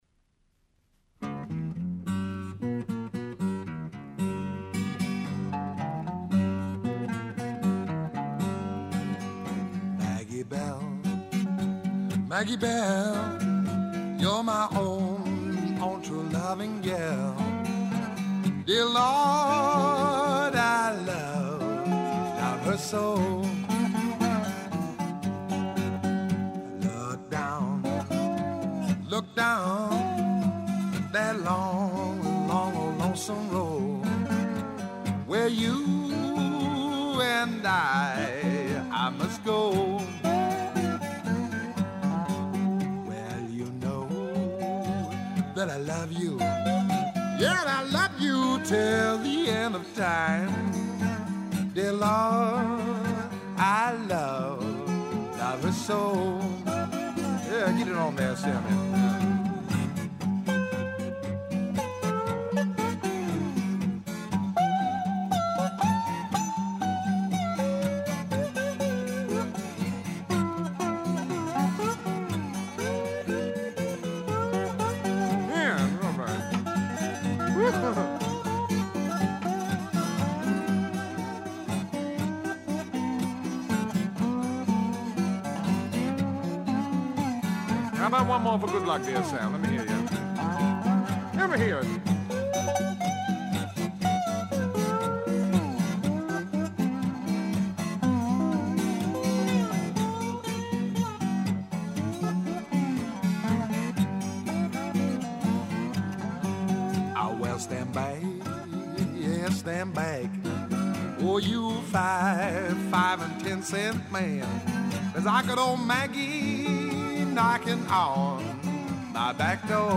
an acoustic tribute